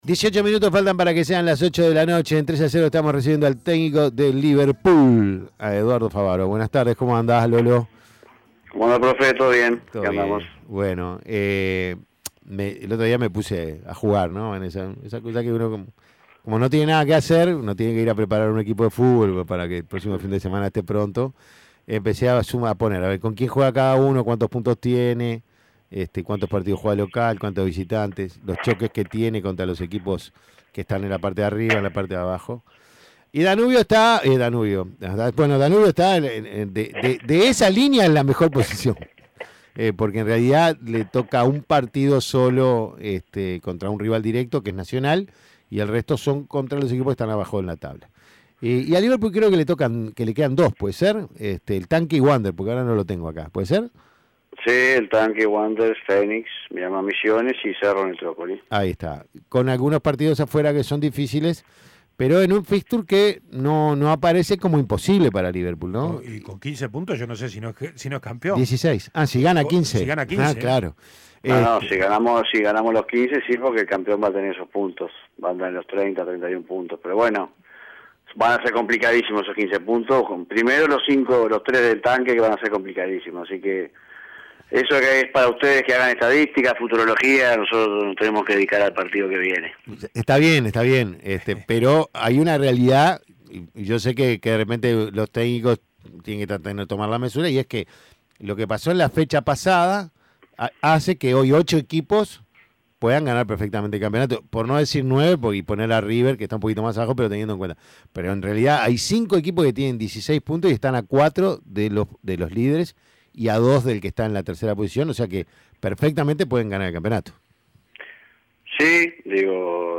Testimonios